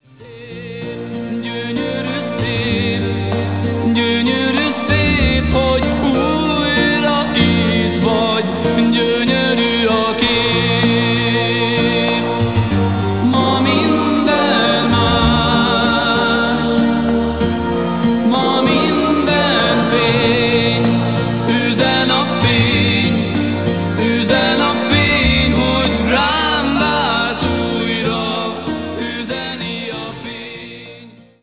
ének
billentyűs hangszerek
dobok
szólógitár
basszusgitár)hu